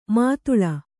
♪ mātuḷa